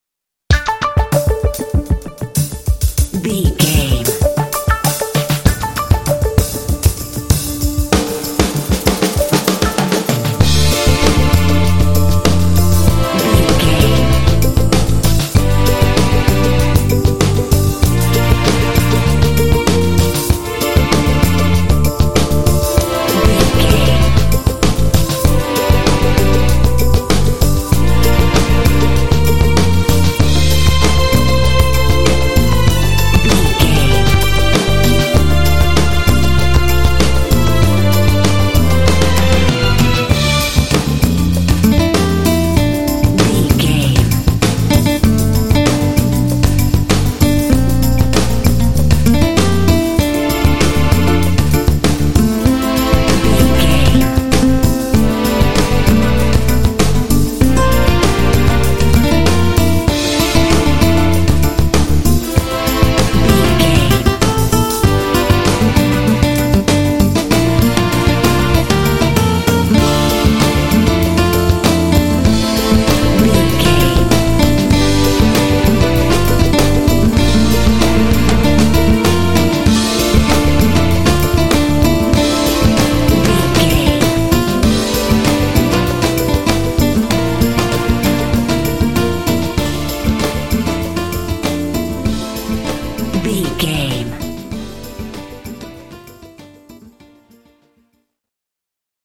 Aeolian/Minor
D
sensual
contemplative
synthesiser
drums
strings
bass guitar
acoustic guitar
synth- pop
new wave
indie